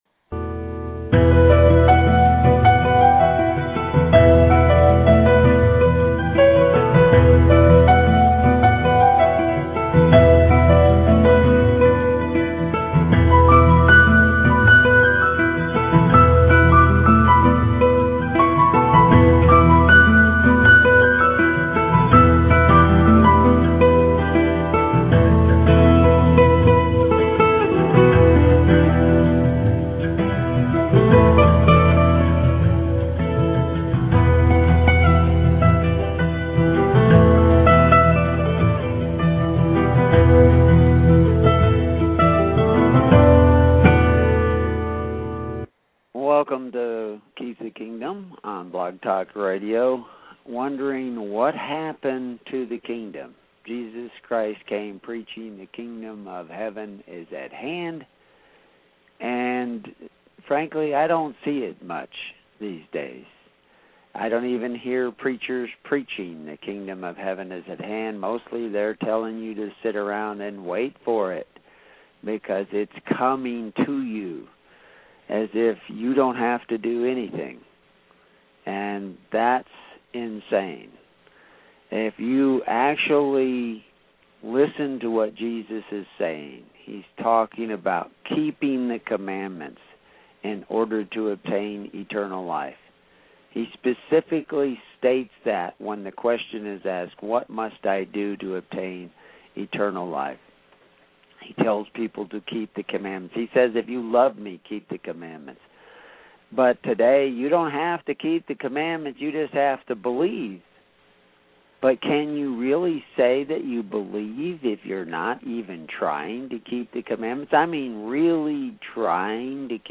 Keys of the Kingdom radio broadcast August 28, 2010